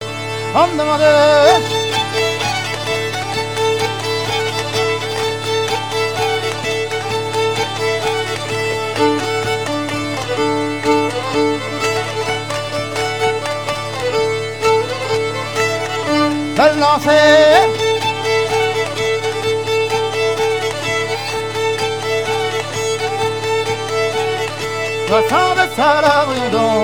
Bocage vendéen
danse : branle : avant-deux